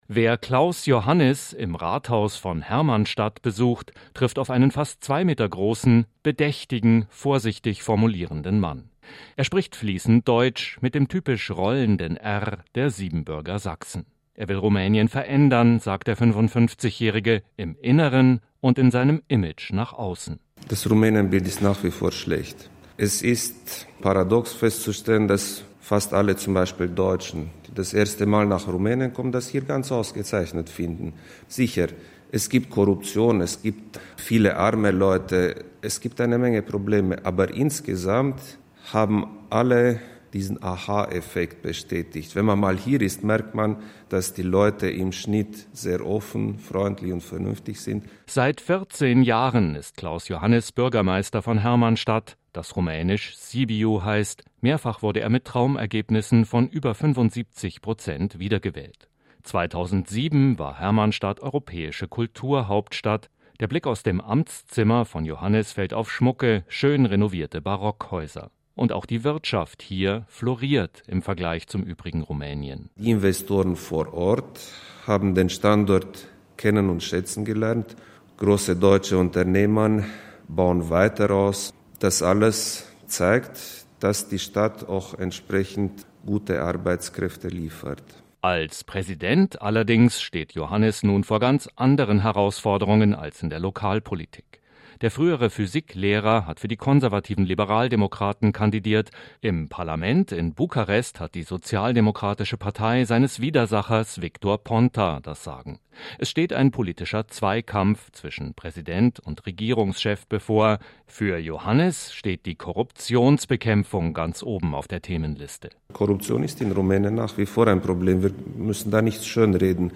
Wer-ist-der-Überraschungssieger-in-Rumänien-Portrait-Klaus-Iohannis.mp3